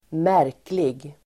Uttal: [²m'är:klig]